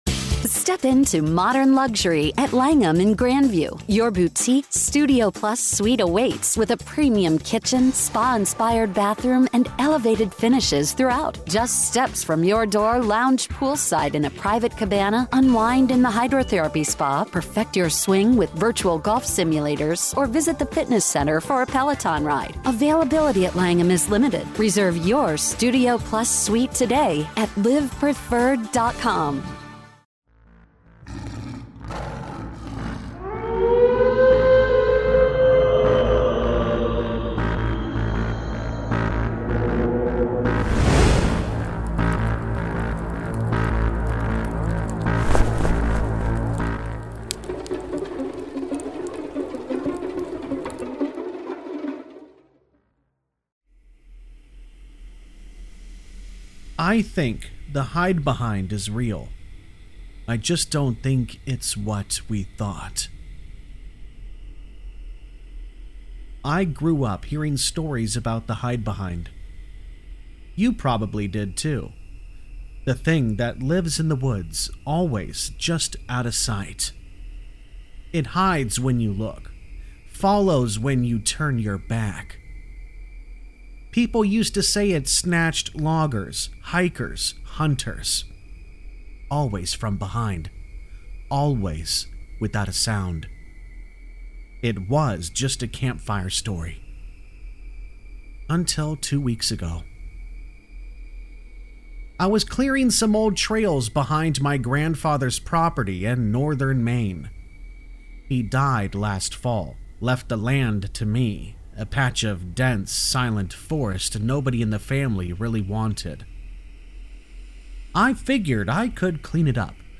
Campfire Tales is a channel that is human voiced that does NOT use a fake Ai voiced simulator program. This channel is focused on Allegedly True Scary Stories and Creepypastas.